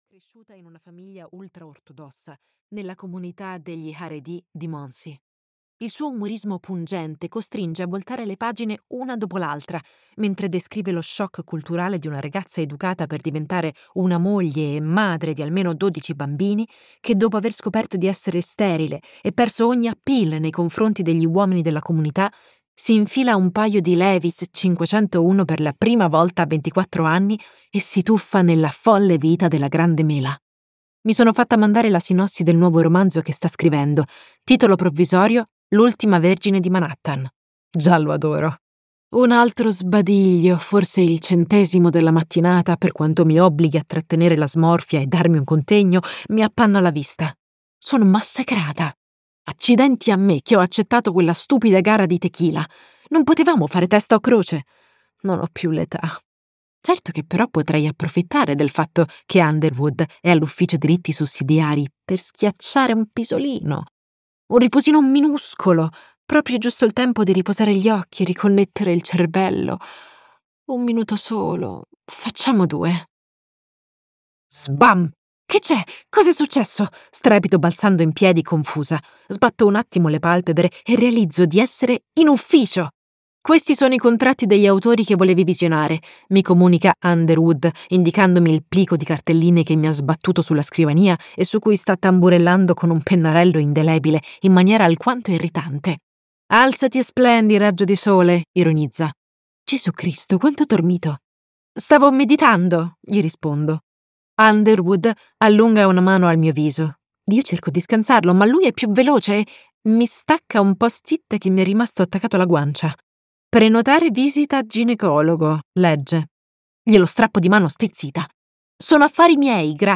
"Ti aspetto a Central Park" di Felicia Kingsley - Audiolibro digitale - AUDIOLIBRI LIQUIDI - Il Libraio